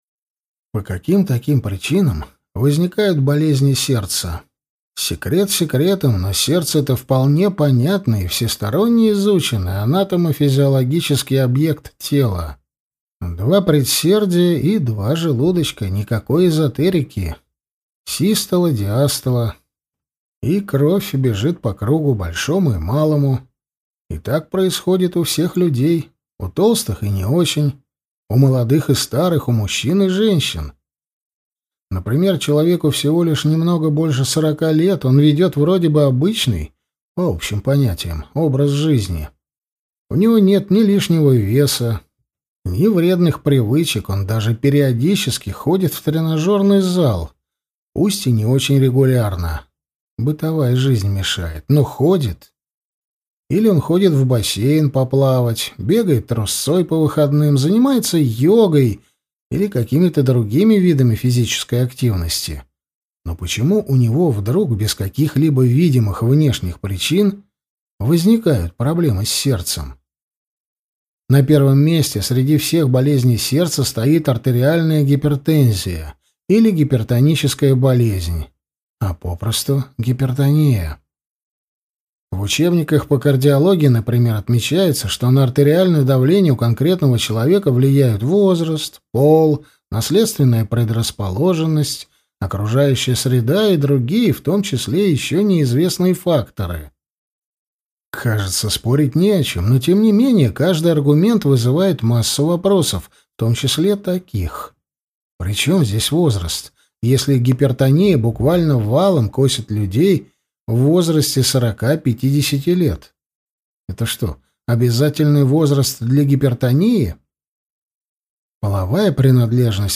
Аудиокнига Ленивая гипертония. Как справиться с истинной причиной высокого давления | Библиотека аудиокниг